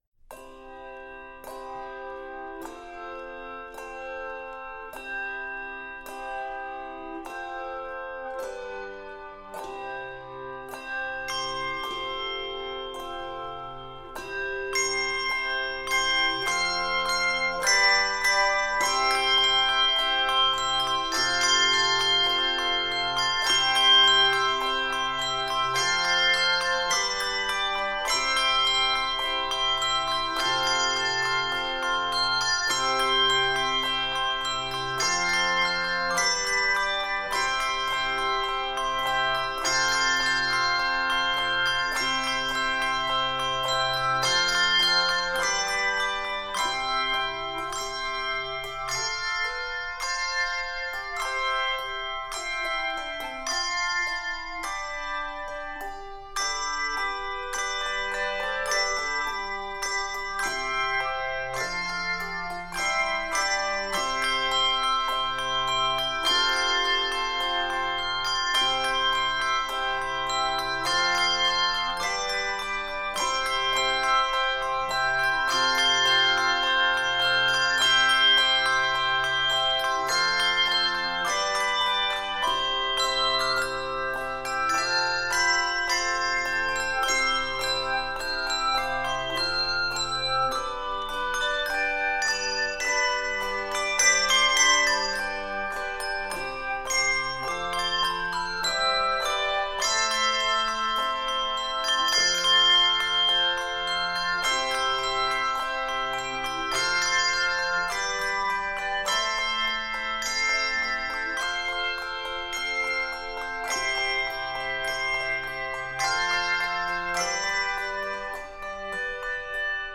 regal arrangement